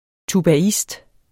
Udtale [ tubaˈisd ]